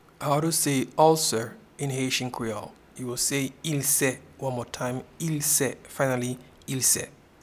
Pronunciation and Transcript:
Ulcer-in-Haitian-Creole-Ilse.mp3